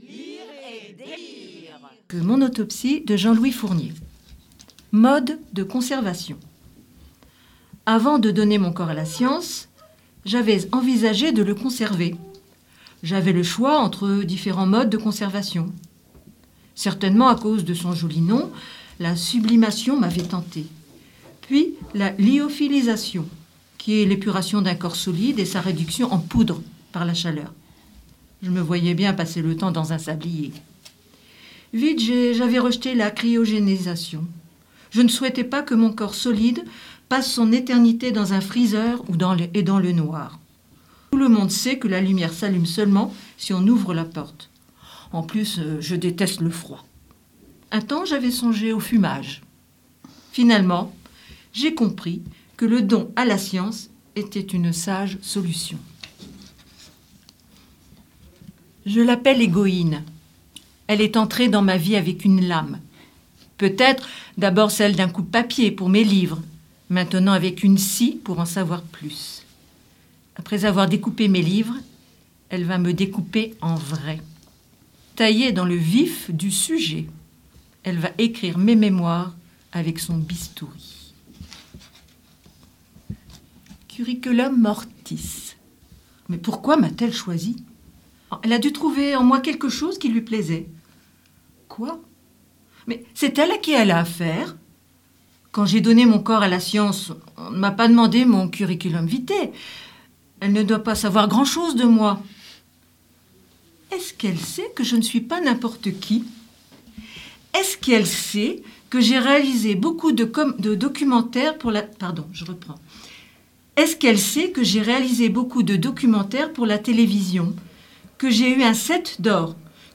Le groupe "Lire et délire" vous présente un extrait des livres suivants :